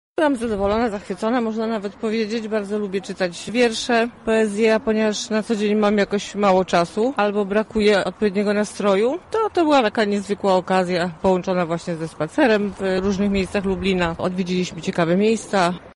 To wszystko w ramach spaceru szlakiem poematu Józefa Czechowicza.
O swoich wrażeniach mówi uczestniczka
Uczestnik 2